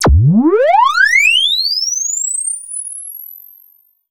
fxpTTE06013sweep.wav